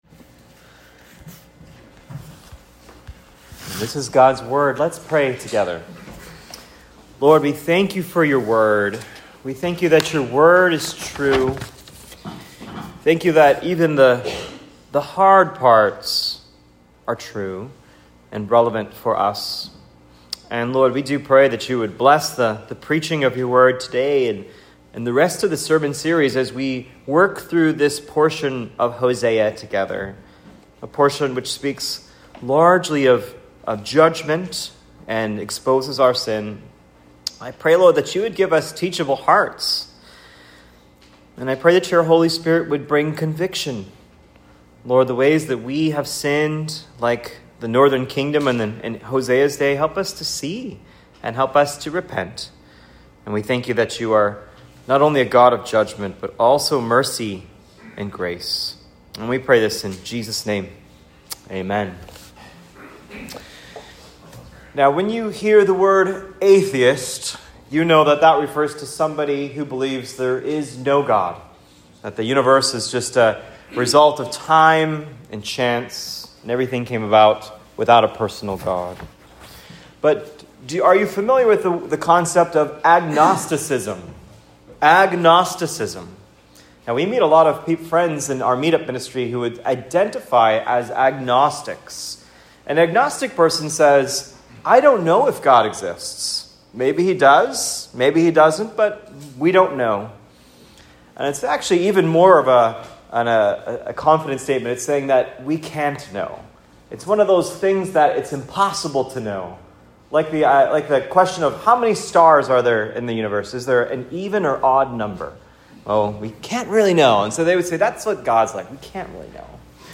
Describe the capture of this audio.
(Preached at Cross of Christ Fellowship on 5.11.25)